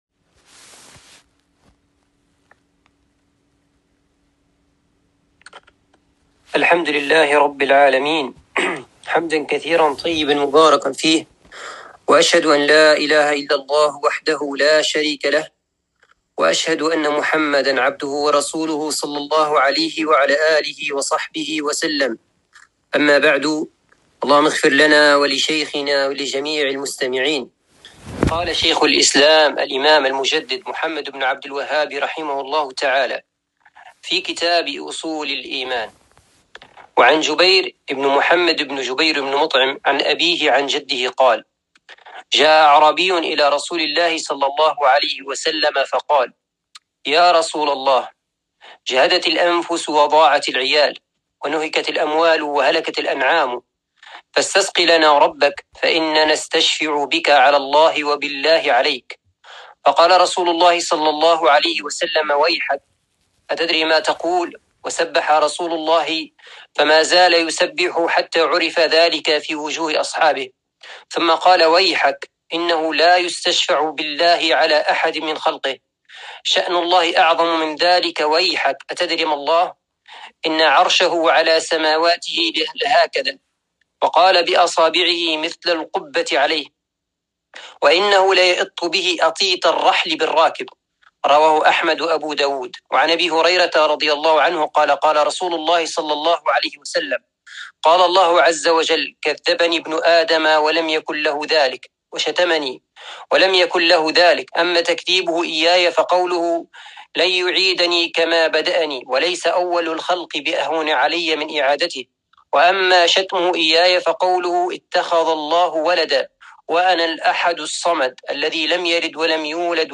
الدرس الثالث من شرح كتاب أصول الإيمان